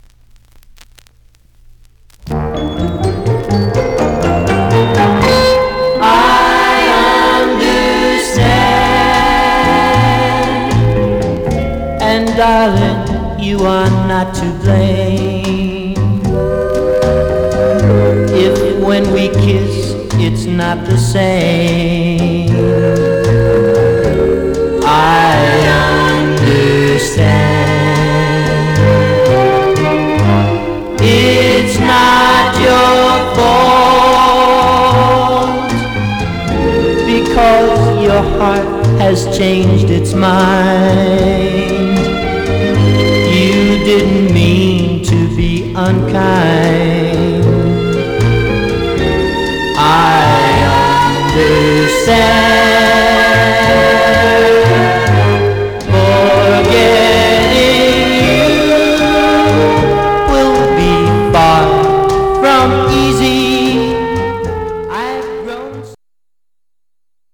Some surface noise/wear Stereo/mono Mono
Teen